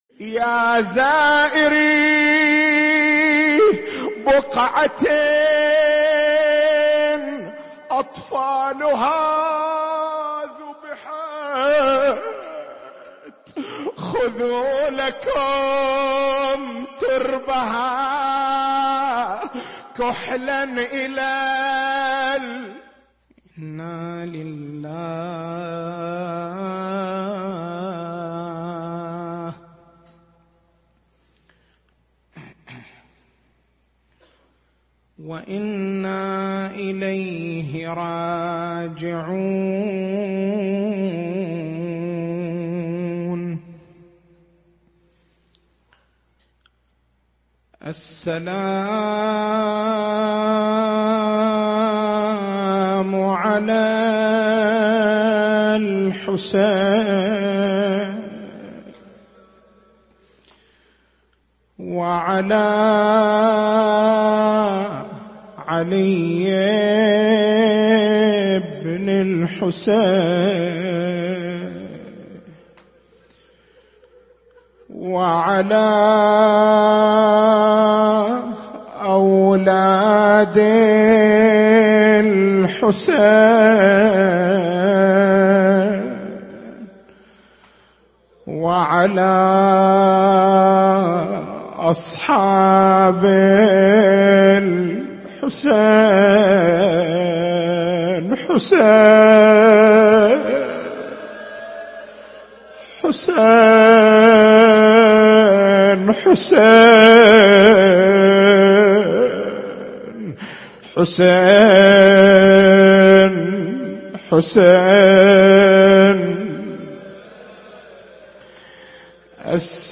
نص المحاضرة